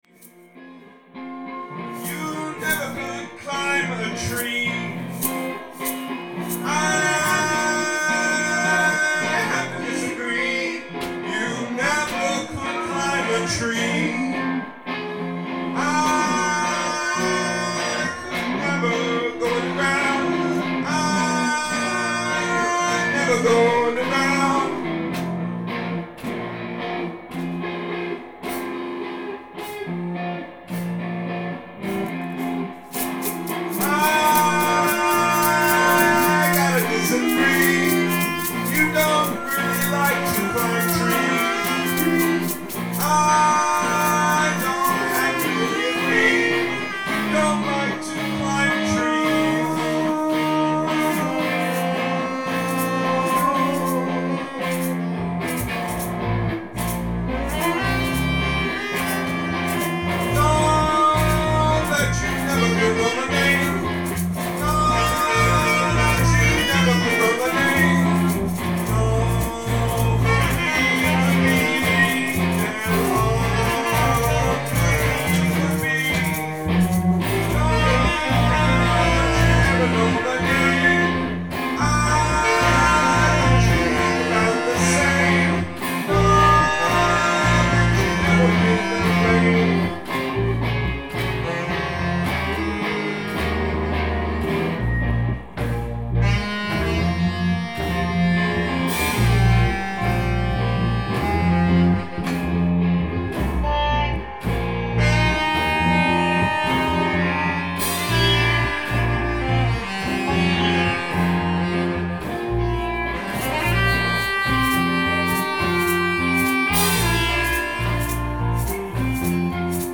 ALL MUSIC IS IMPROVISED ON SITE
moog/keys
alto sax
drums
bass